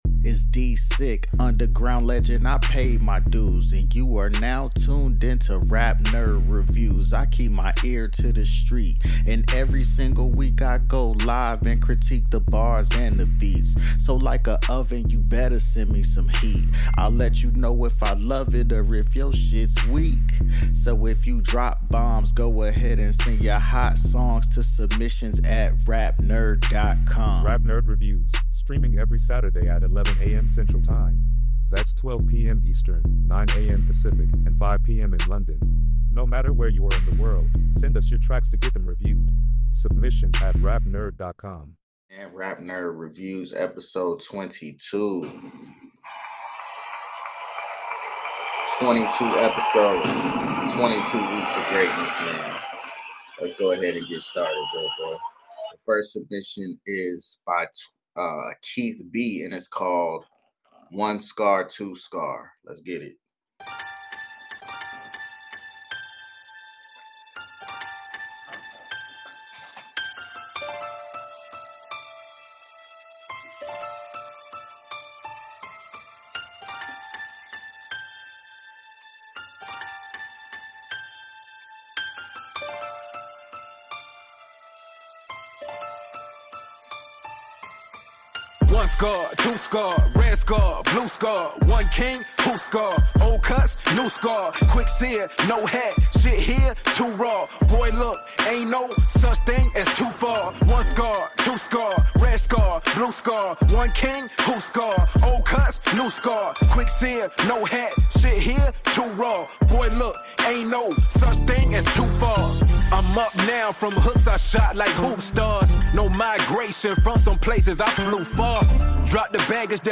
Rap Nerd Reviews Episode 23 | Indie Hip Hop Music Review Show.